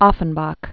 fən-bäk, ŏfən-, ô-fĕn-bäk), Jacques 1819-1880.